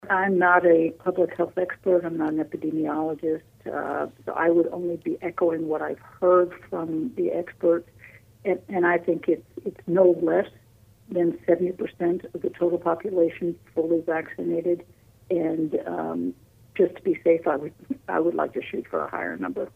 Governor Laura Kelly spoke with News Radio KMAN this week during an exclusive interview heard Wednesday on KMAN’s In Focus.